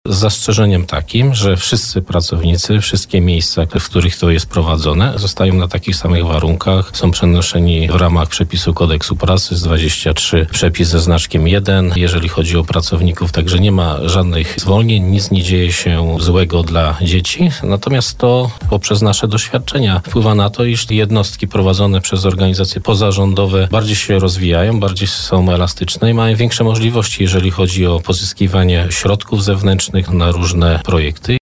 Zastępca prezydenta miasta Piotr Kucia przekonywał na naszej antenie, że likwidacja-przekształcenie odbędzie się z zachowaniem dotychczasowego poziomu zatrudnienia, bez szkody dla dzieci i z zachowaniem wszystkich dziesięciu miejsc, w których młodzi ludzie mogą liczyć na wsparcie.